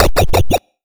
sfx-atck-fury_claw.Dxw6uNCy.wav